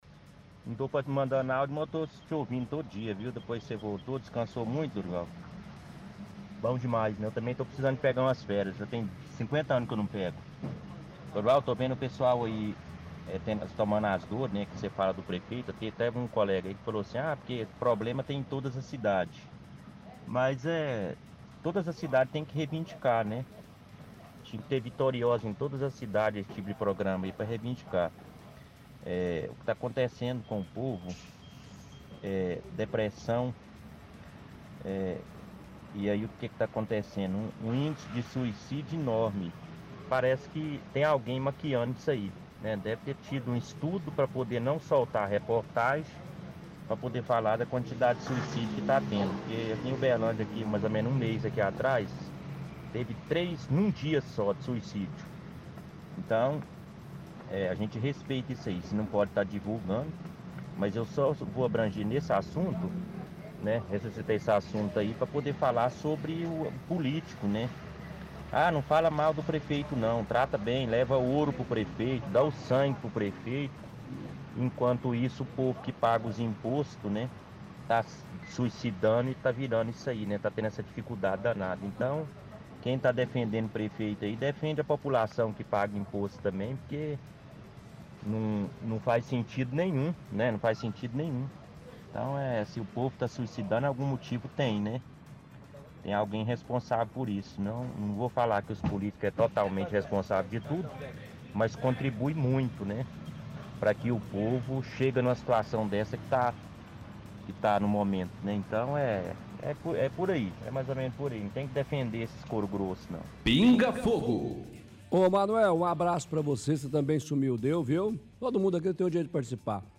– Ouvinte fala que é necessário falar mal da política de Uberlândia.